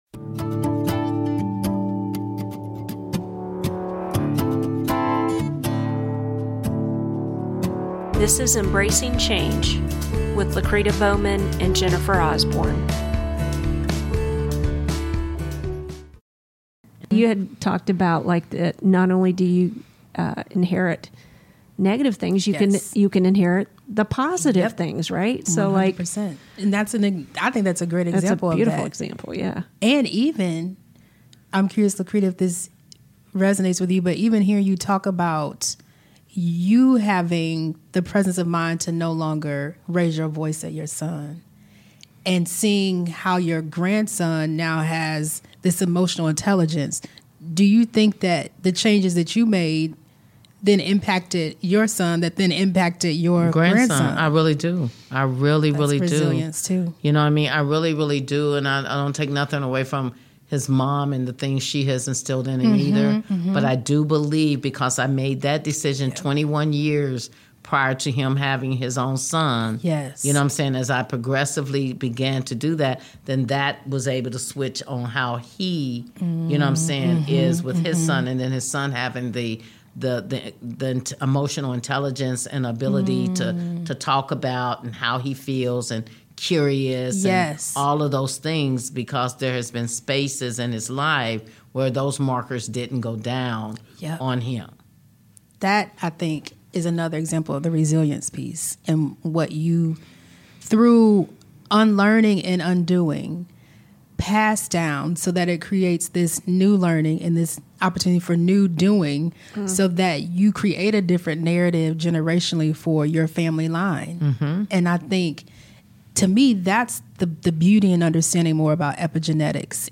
Unraveling: healing versus cure and deep pain - How Trauma Divides - Interview